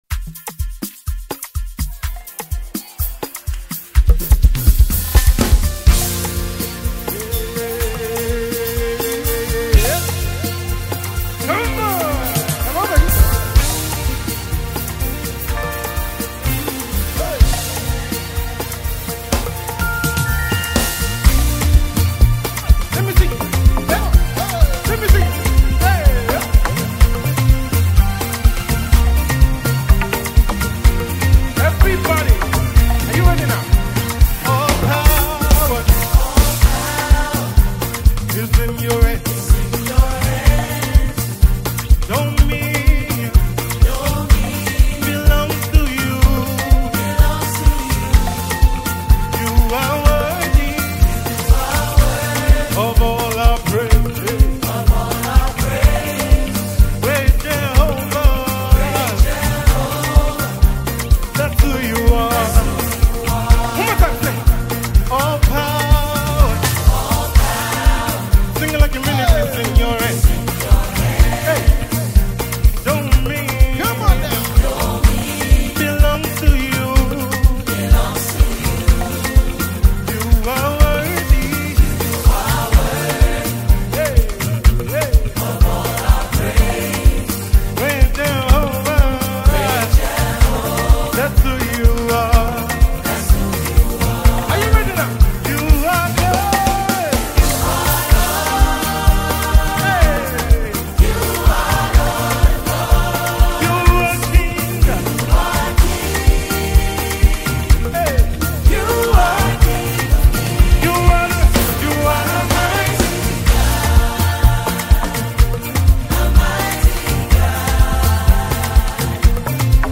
African Praise Music